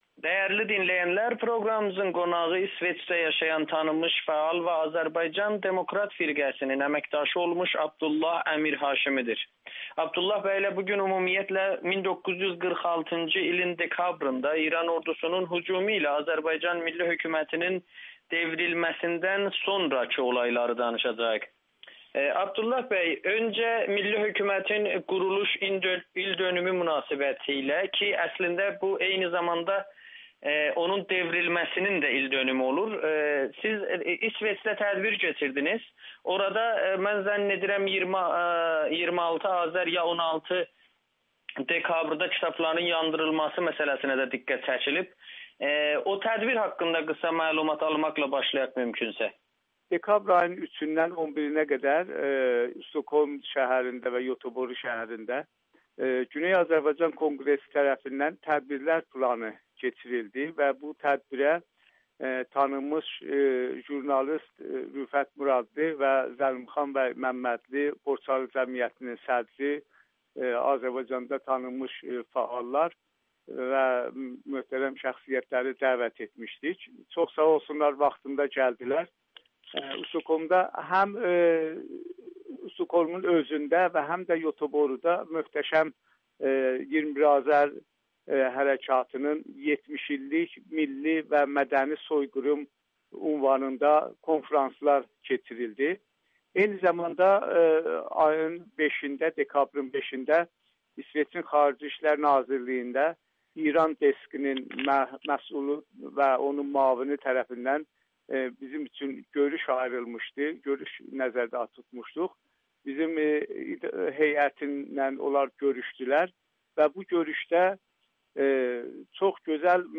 Audio-Müsahibə